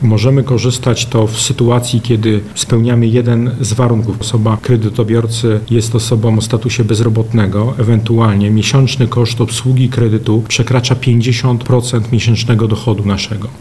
Drugą formą pomocy jest Fundusz Wsparcia Kredytowego. Kto może z niego skorzystać, mówi poseł PiS Andrzej Kosztowniak: